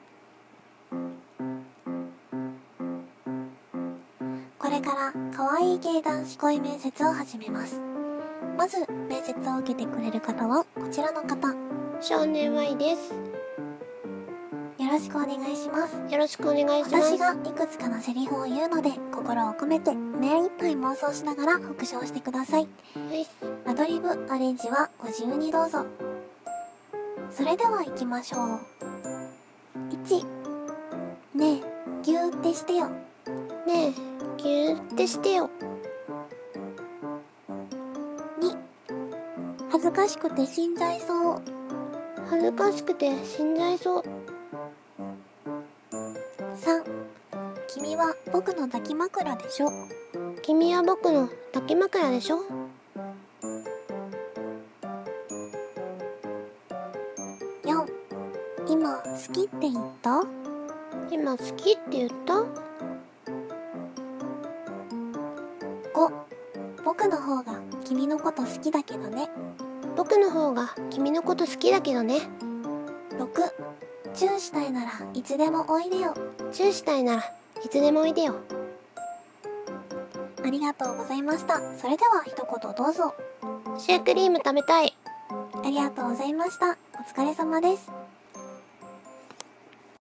【可愛い系男子声面接】